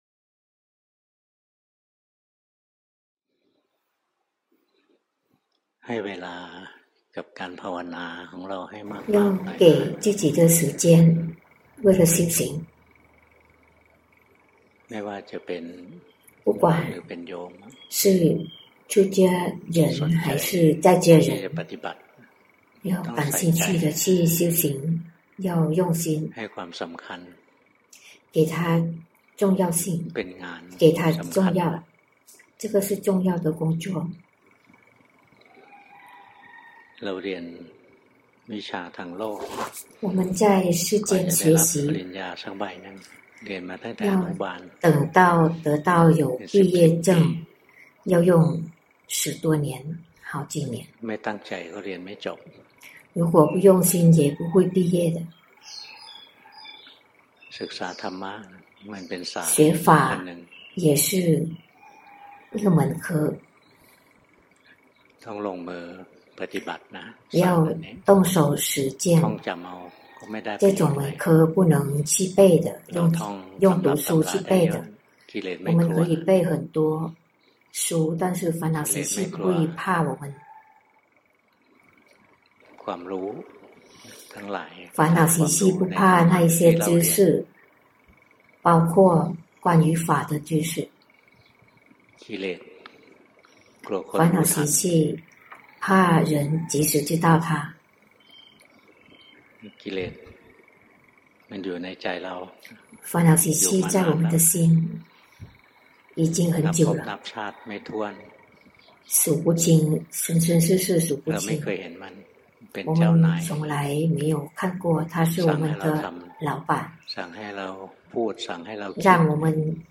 法談摘錄
泰國解脫園寺 同聲翻譯